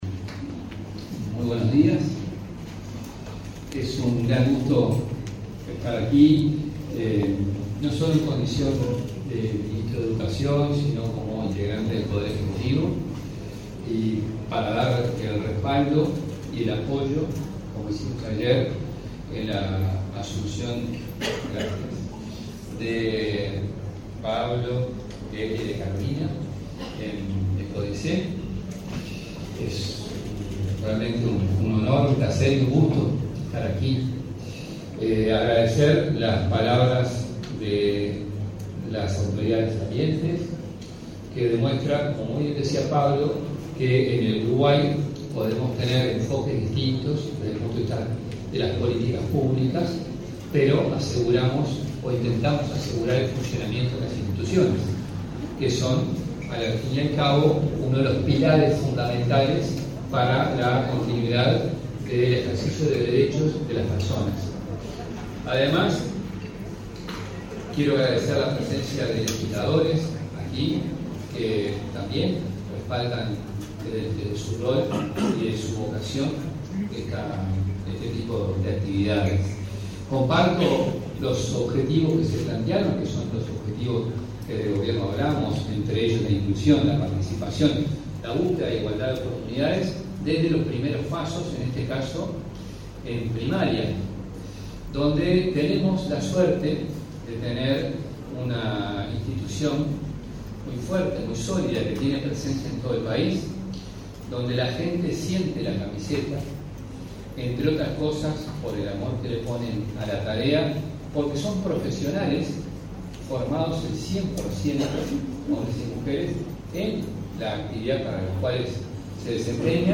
Palabras del ministro de Educación y Cultura, José Carlos Mahía